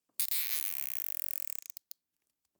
household
Zip Ties Secure 9